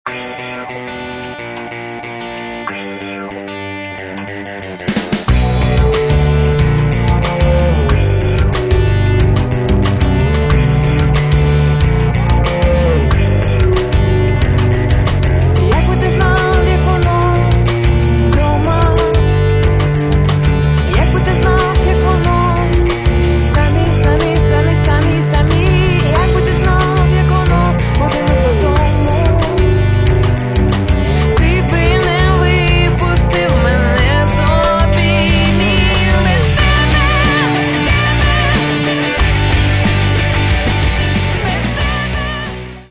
Каталог -> Рок та альтернатива -> Поп рок